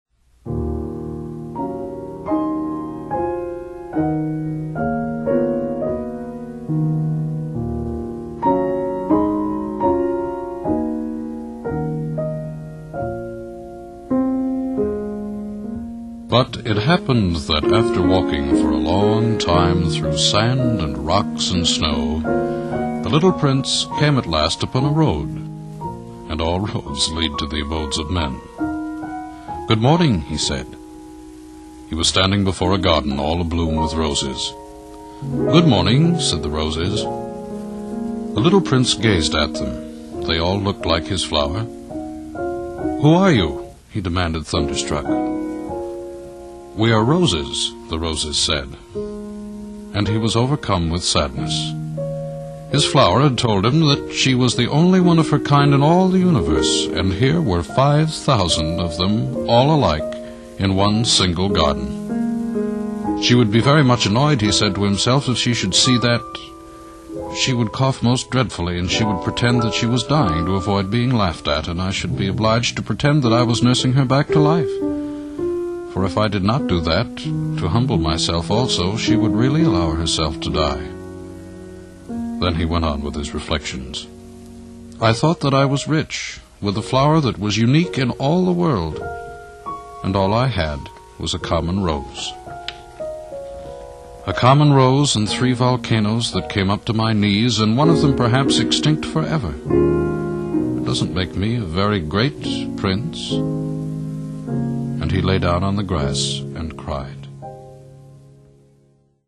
"eloquently delivered," said another.
He introduces his 1960 reading with imrpovisational music which he continues to play throughout the narration.